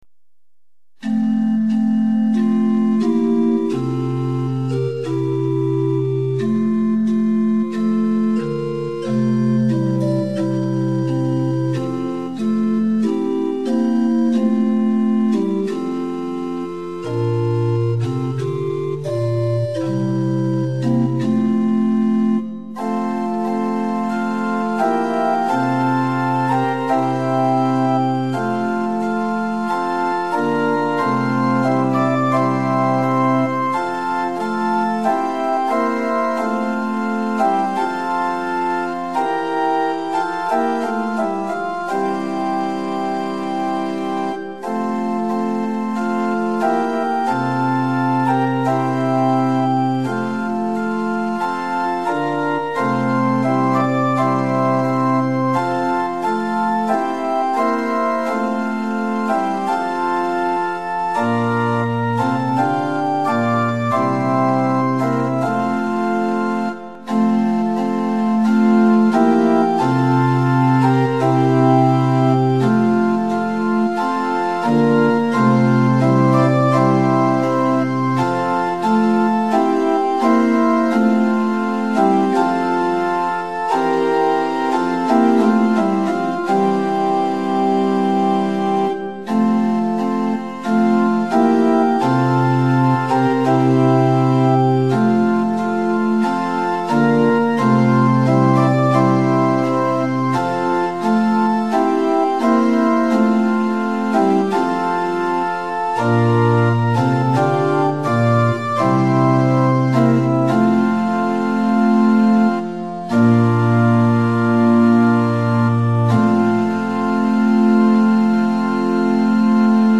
♪賛美用オルガン伴奏音源：
・柔らかい音色(ロア・フルート8')部分は前奏です
・はっきりした音色(プリンシパル8'+4')になったら歌い始めます
・節により音色が変わる場合があります
・間奏は含まれていません
Tonality = F
Pitch = 440
Temperament = Equal